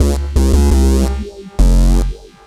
BASS25LP01-R.wav